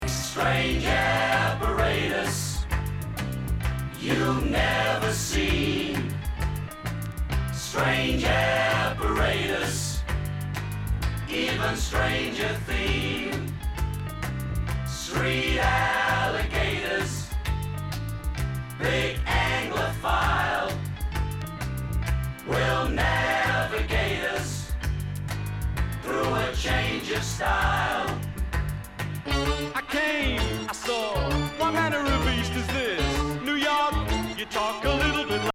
ヒネクレまくりな世界観と実験感とポップ感のバランスがスバラしい！！